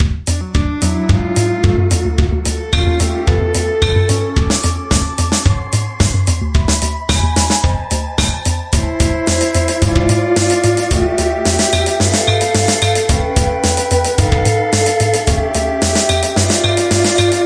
描述：这些ar 175 bpm合成器背景层或在你的混音中提出合成器导线可用于鼓和贝司。
Tag: 高科技 声音 背景 高科技 效果 FX 低音 俱乐部 合成器 样品 配音步 恍惚 毛刺跳 电子 狂欢 样品 电火花 房子 舞蹈